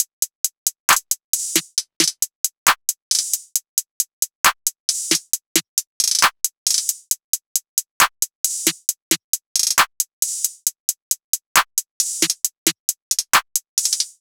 SOUTHSIDE_beat_loop_cash_top_135.wav